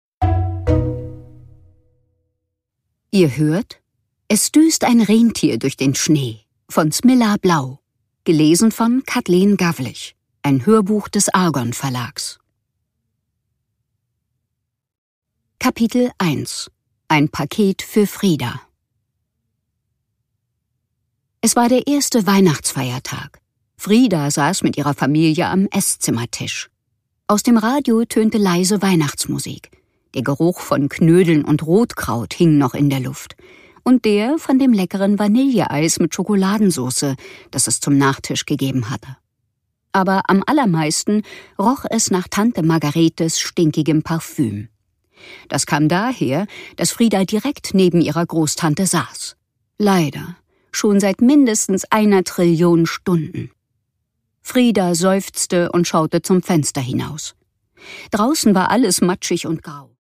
Produkttyp: Hörbuch-Download
Ihre klugen und lebhaften Interpretationen und ihre große Stimmenvielfalt machen jedes ihrer Hörbücher zu einem Erlebnis.